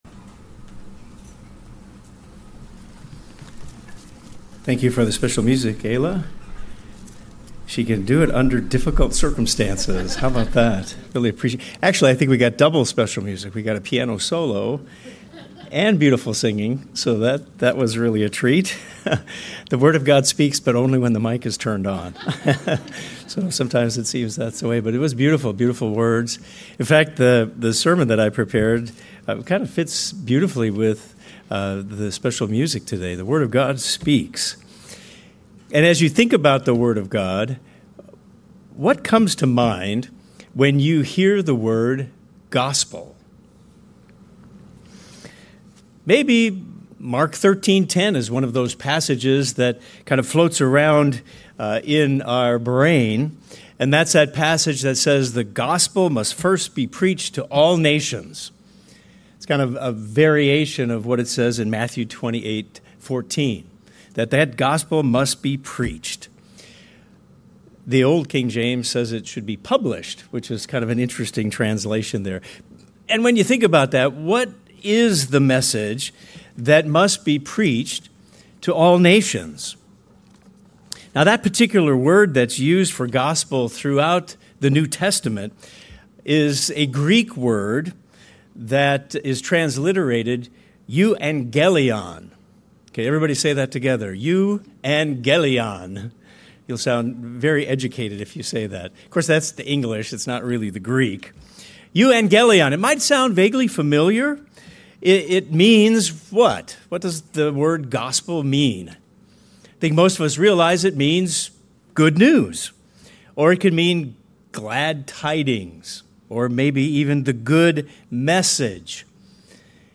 This sermon digs into the gospel in its full picture—not just some future hope, but something powerful and real you can live out today.